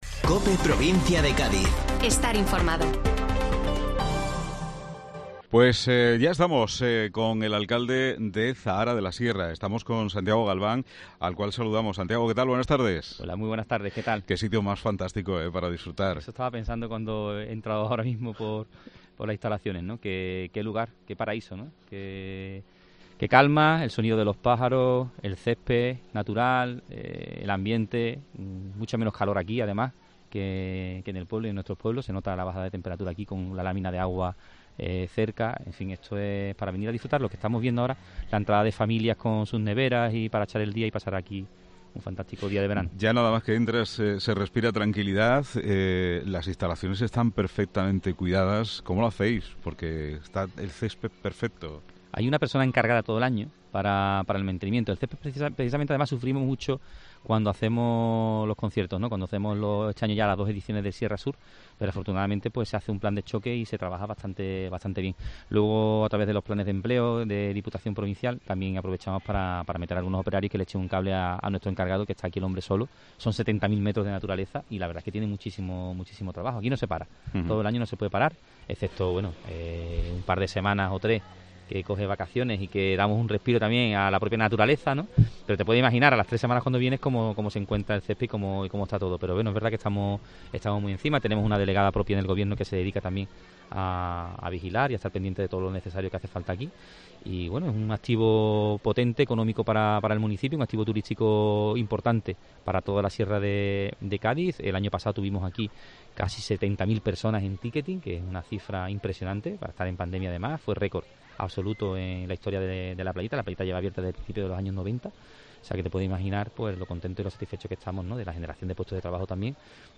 Mediodía COPE desde "La Playita" de Zahara de la Sierra. Area Recreativa Arroyomolinos